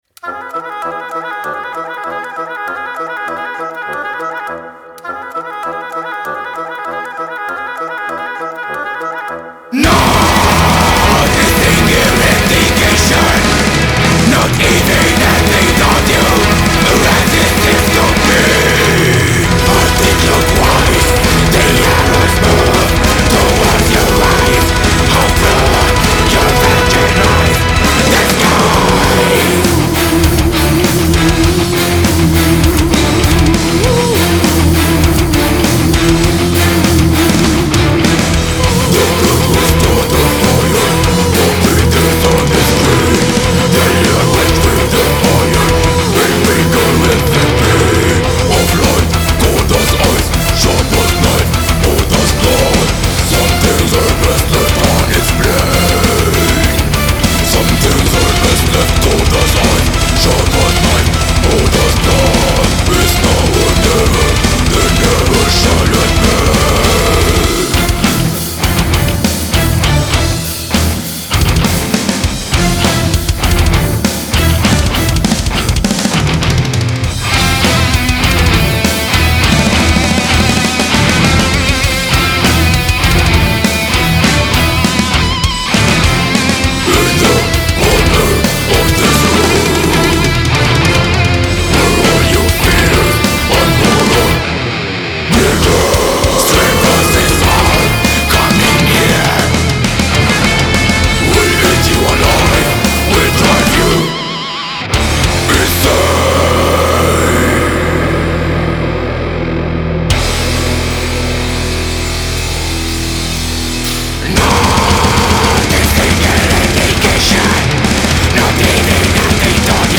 Black Metal Фолк Рок